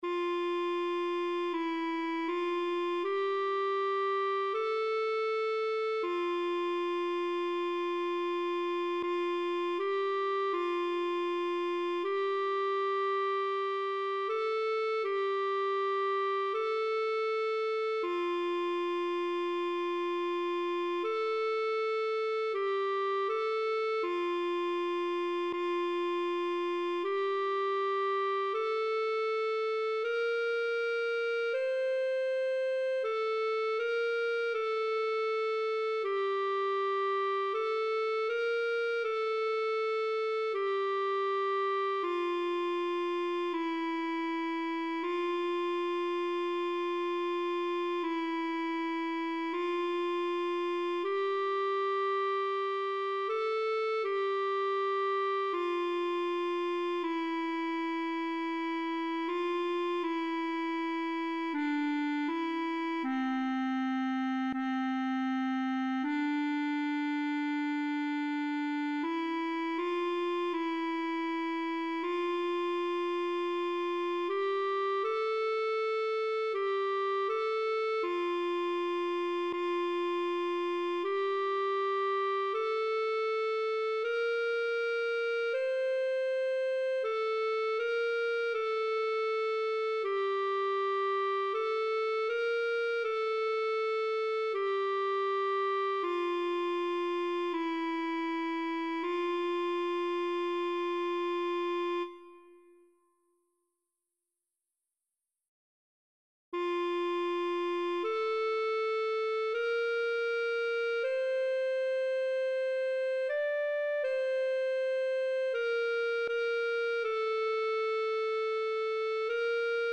Heruvic-Lungu-1.mp3